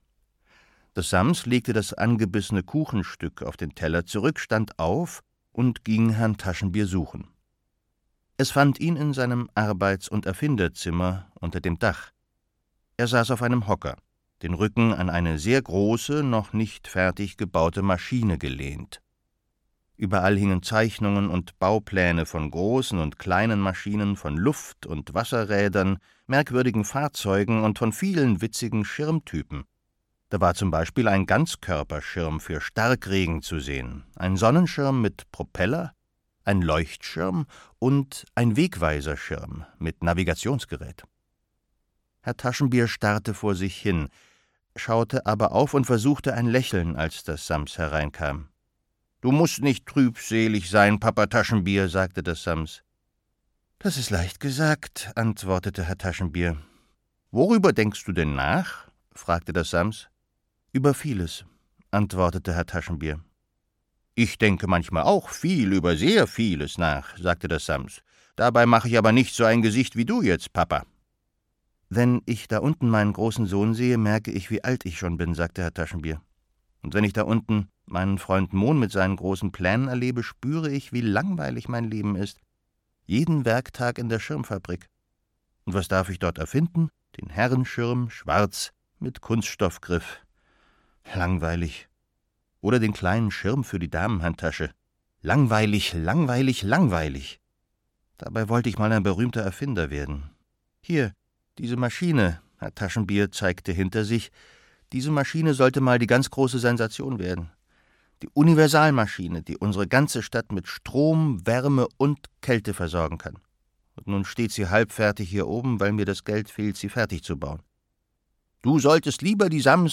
Das Sams 7. Sams im Glück - Paul Maar - Hörbuch